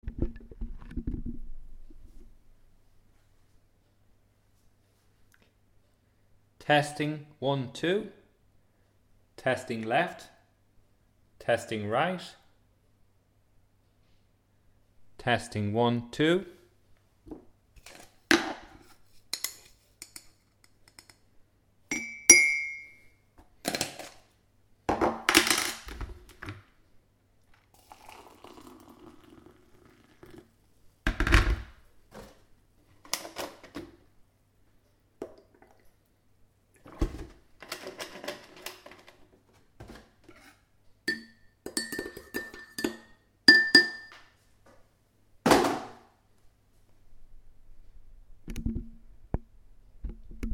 ZoomH1DigitalSource.mp3